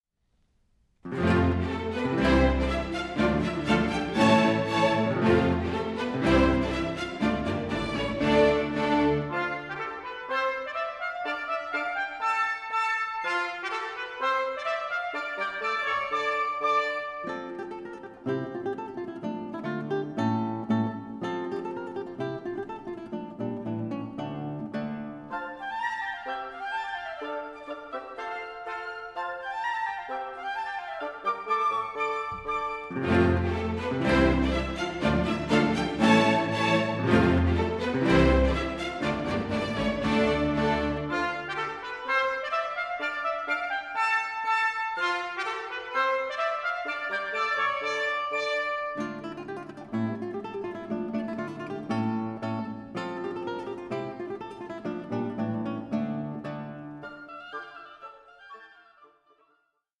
for Guitar and Small Orchestra
Allegro ma non troppo